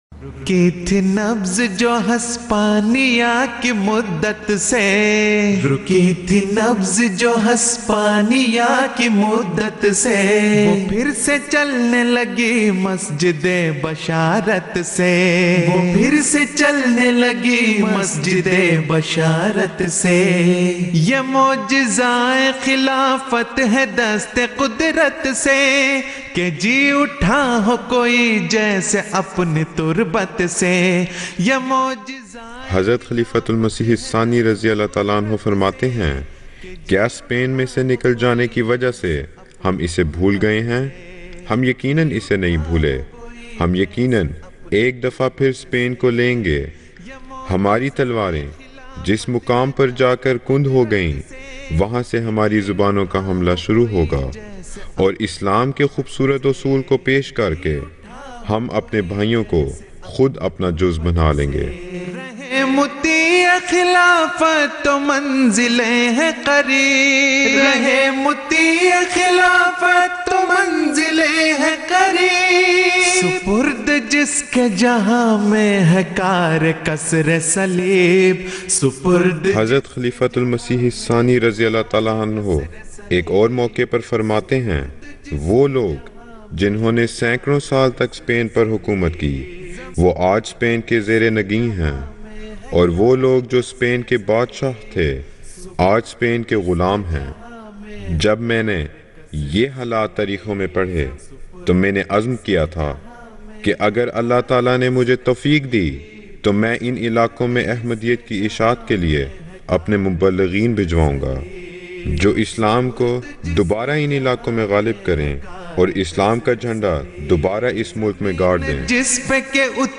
آواز: خدام گروپ Voice: Group Khuddam